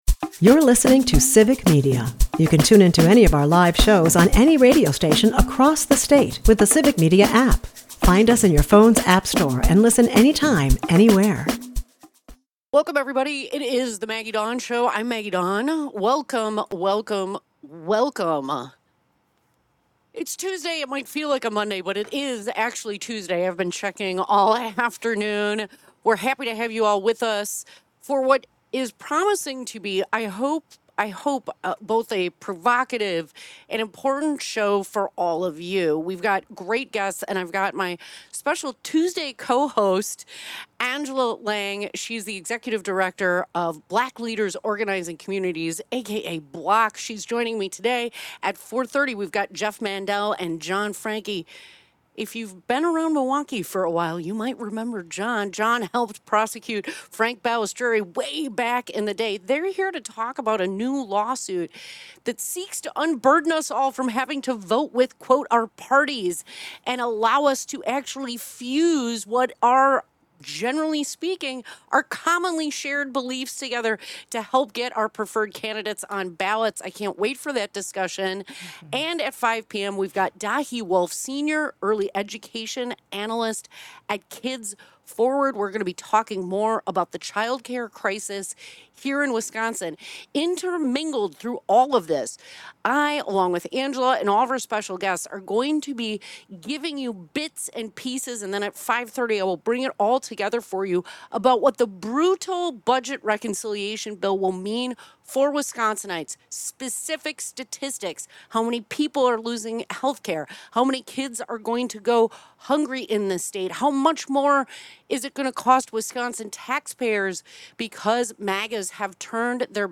In a compelling discussion on Wisconsin’s political future